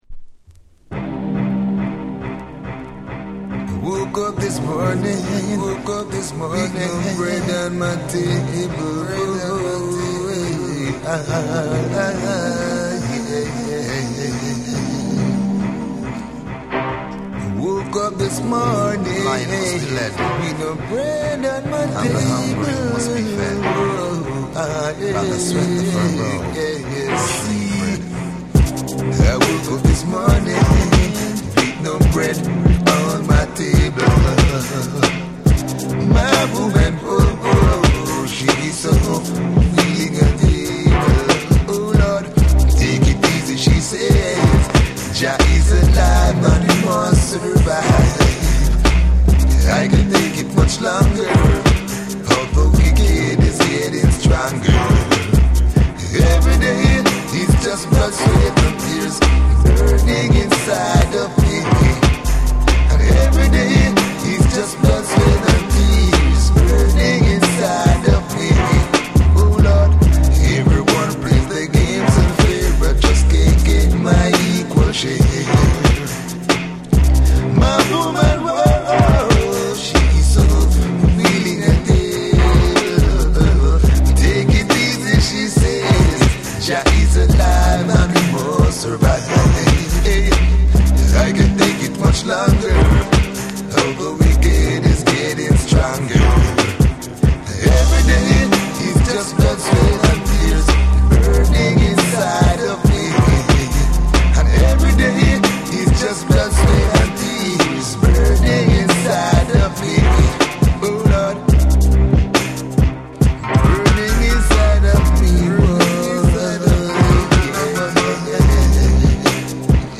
REGGAE & DUB / BREAKBEATS